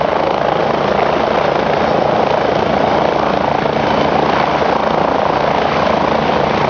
rotor.wav